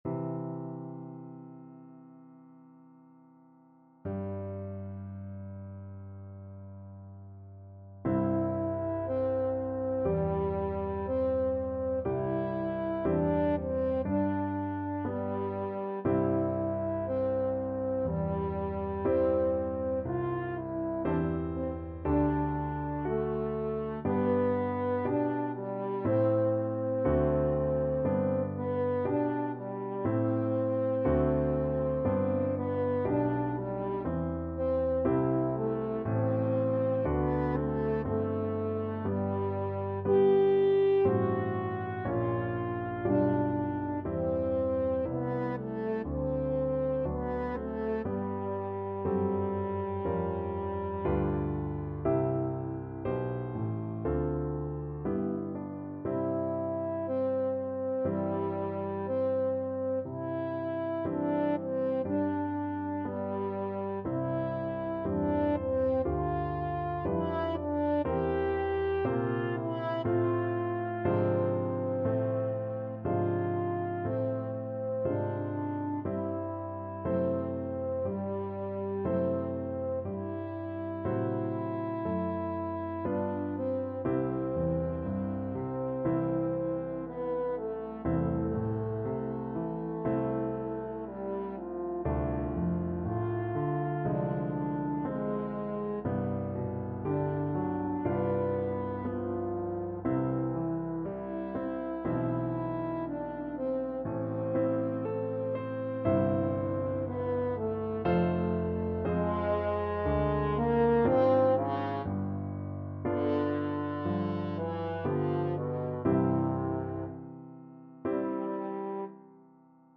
French Horn
B3-G5
C major (Sounding Pitch) G major (French Horn in F) (View more C major Music for French Horn )
~ = 100 Adagio =c.60
2/4 (View more 2/4 Music)
Classical (View more Classical French Horn Music)
brahms_violin_concerto_2nd_HN.mp3